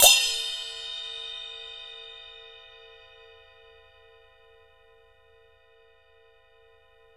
2 CYMBALS -L.wav